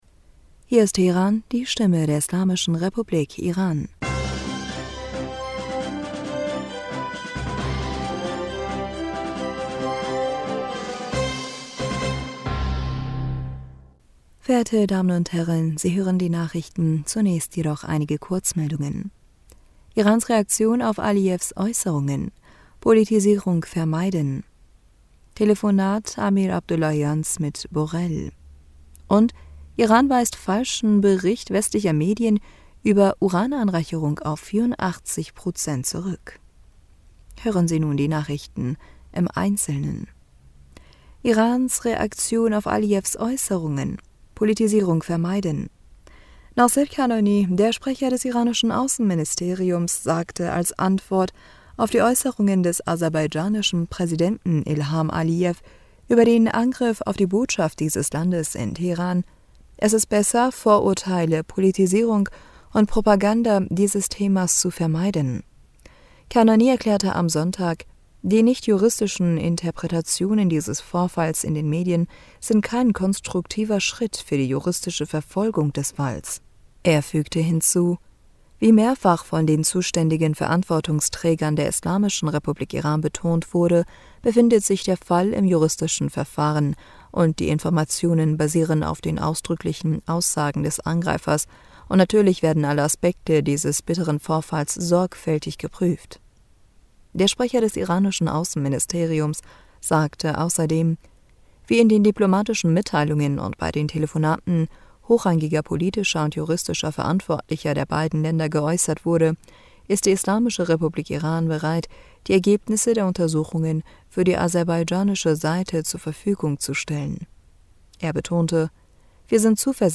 Nachrichten vom 20. Februar 2023